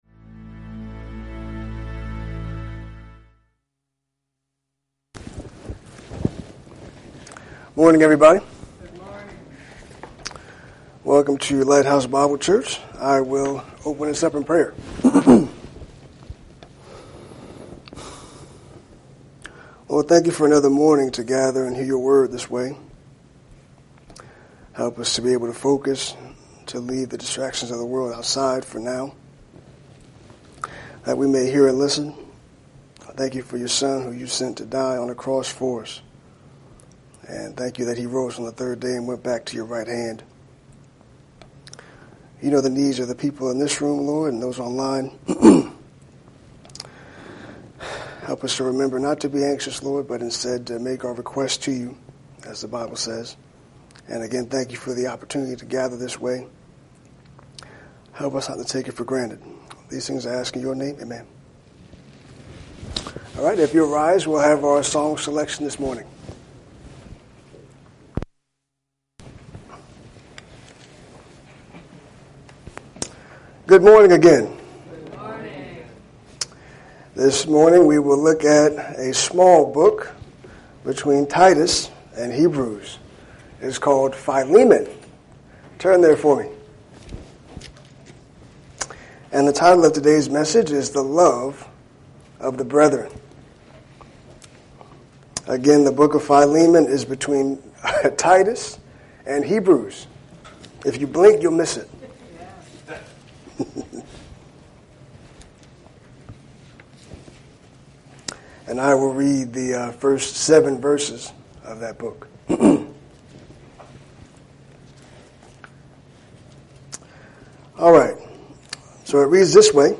Lighthouse Bible Church Sunday, August 17, 2025